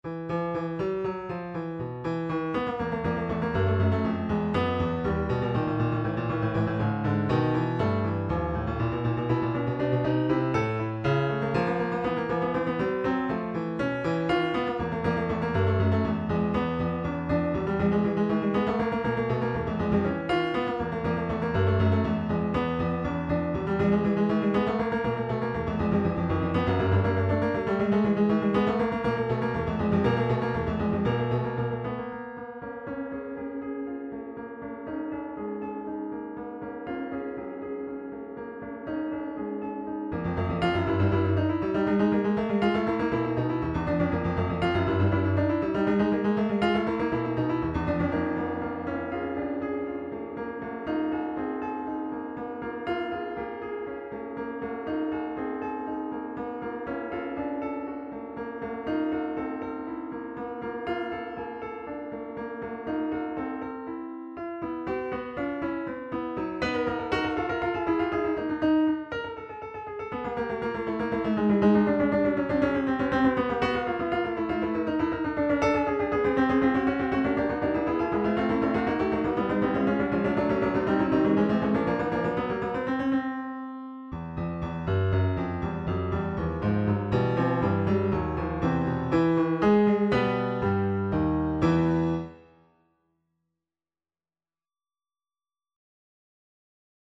Looking for any comments regarding mastering, finishing touches, etc. I'm using the Steinway D Prelude model.
The piece was then transformed into a piece for 2 12et keyboards which can play quartertones.
acidbach3-acoustic.mp3